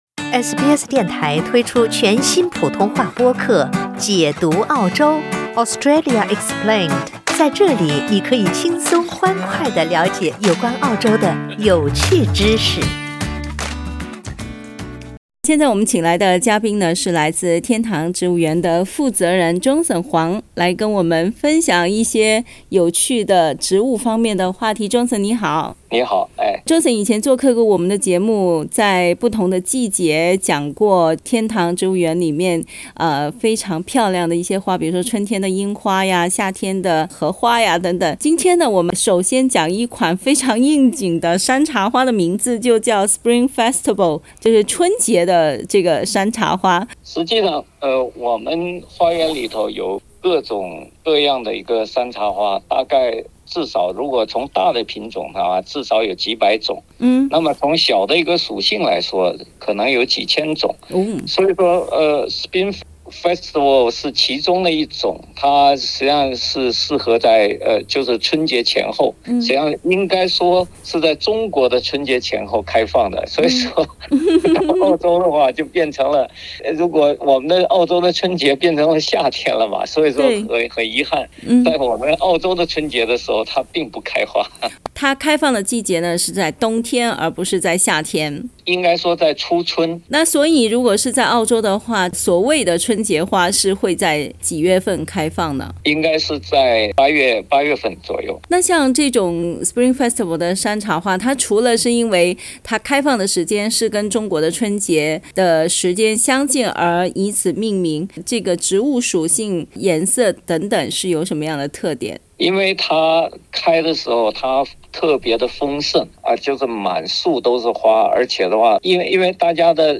在新州的一个植物花园，种植了一种特有的山茶花，名字就叫“春节山茶花”。（点击图片收听报道）